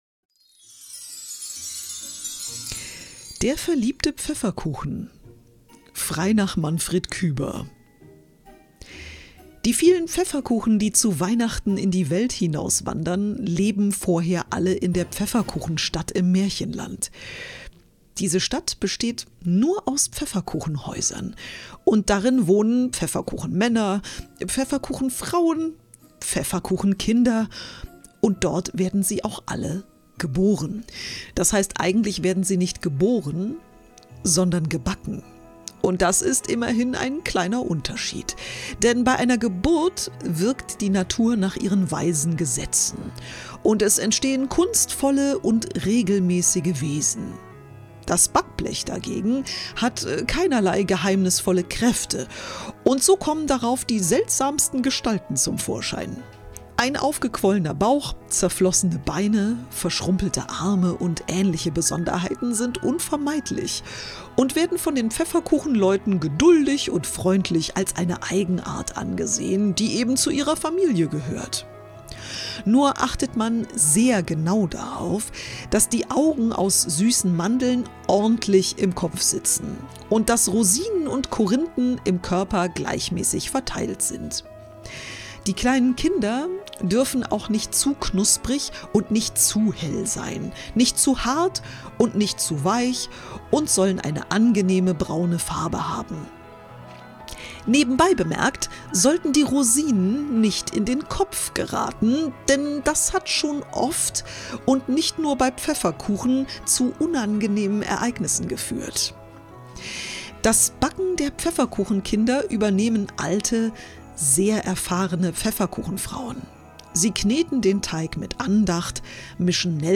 In diesem Special findest du vier verschiedene Weihnachtsmärchen und Weihnachtsgeschichten, vorgelesen mit ruhiger Stimme – wie ein kleines Hörbuch für die Adventssonntage.